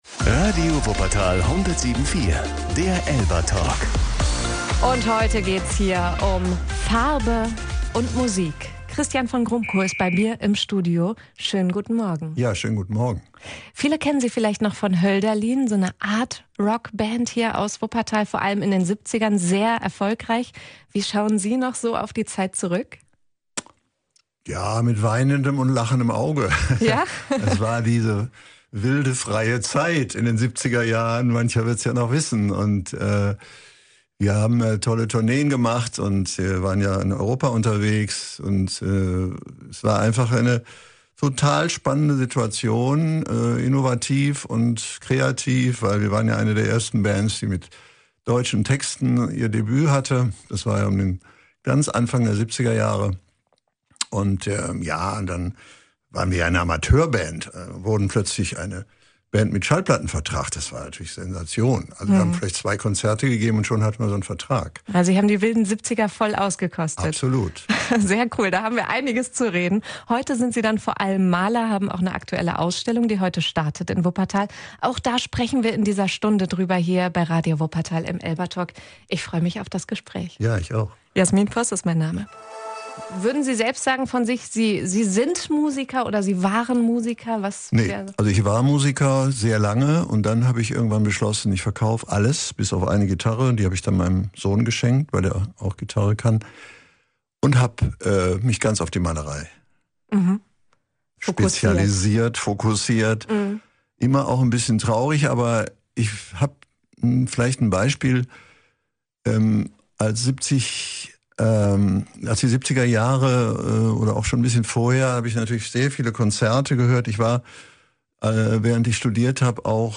ELBA-Talk